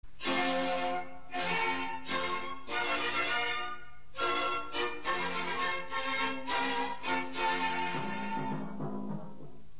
Музыкальная заставка